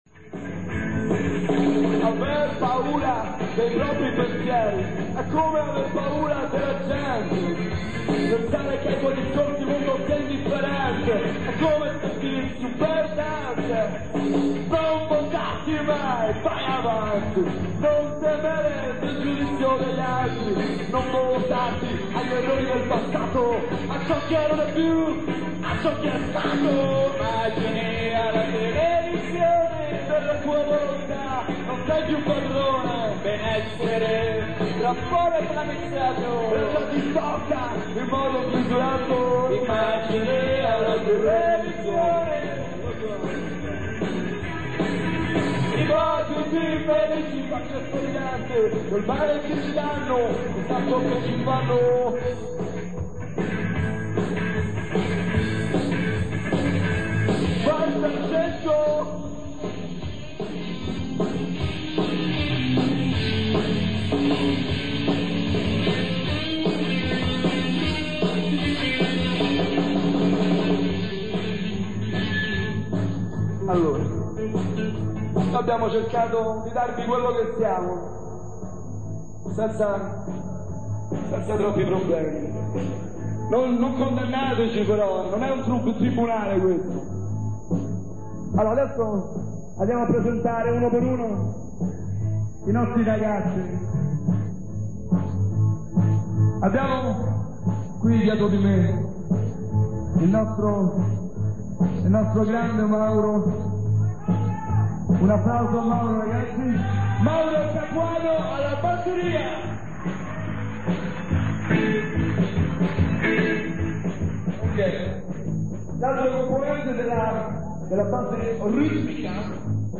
live 93